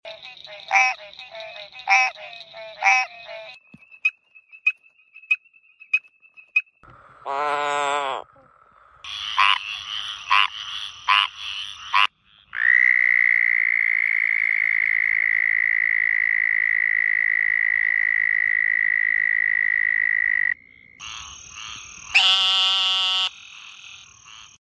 Male frogs have developed a variety of sounds to attract females. Whether it's a cool spring day or a summer night, frogs often call from wetlands.